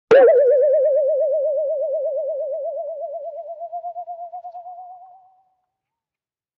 CARTOON - TWANG 02
Category: Sound FX   Right: Both Personal and Commercial